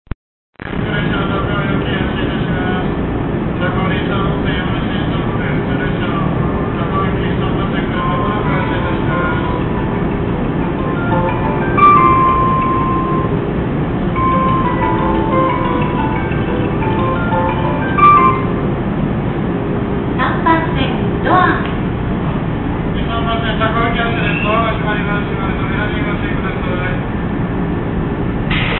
ATOS第１号線ということで、女声の放送しかありません。
このメロディは、現在青梅線の一部駅で使われているメロディです(1・2番線も同じ事)